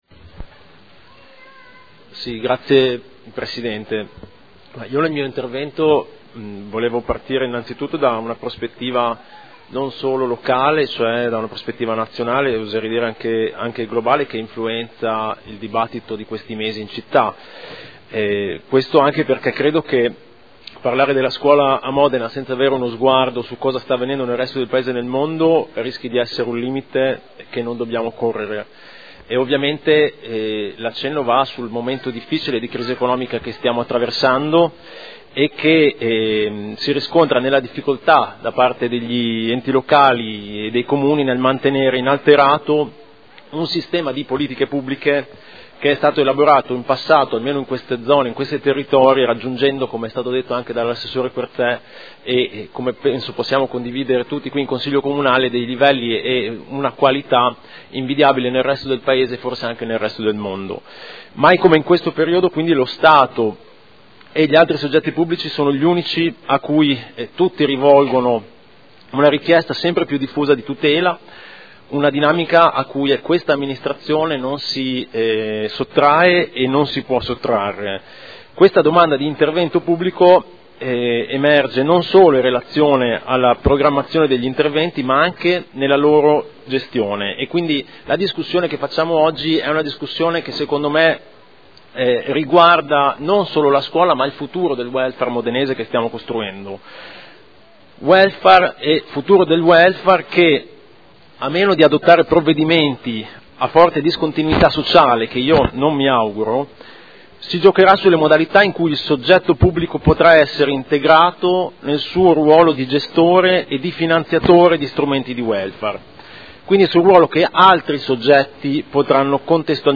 Seduta del 03/05/2012. Dibattito su proposta di deliberazione, emendamenti e Ordine del Giorno sulle scuole d'infanzia comunali
Audio Consiglio Comunale